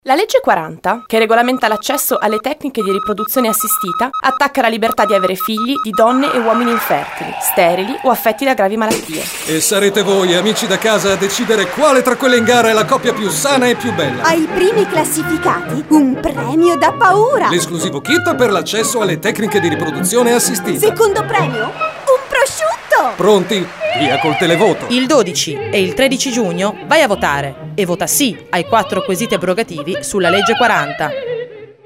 SPOT 4| televoto